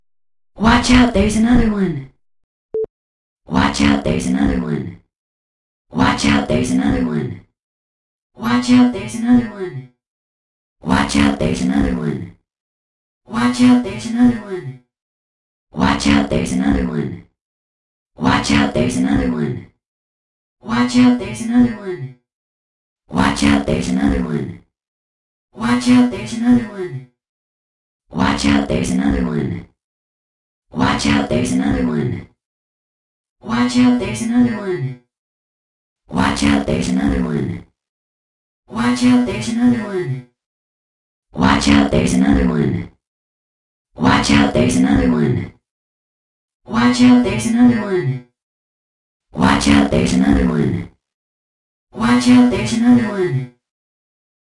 之前之后的混音（更多的机器人）。